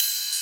VEC3 Cymbals Ride 07.wav